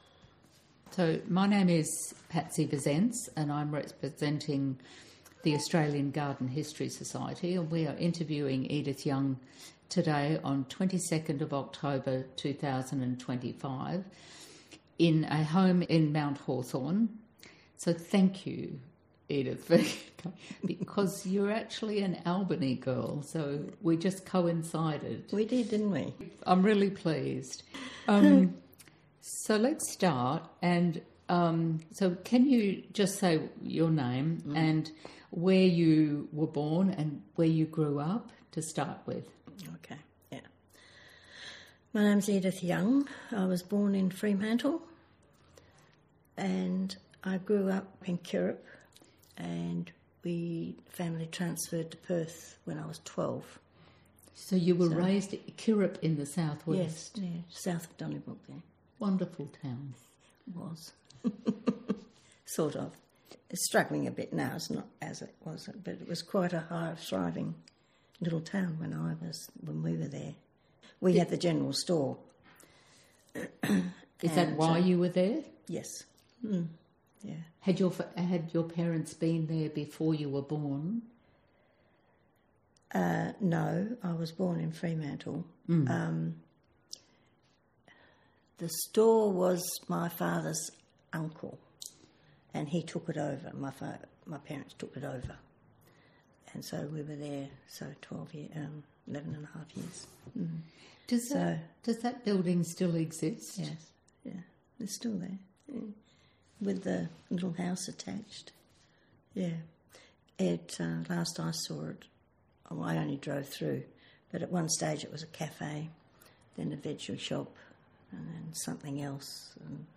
Interview Recording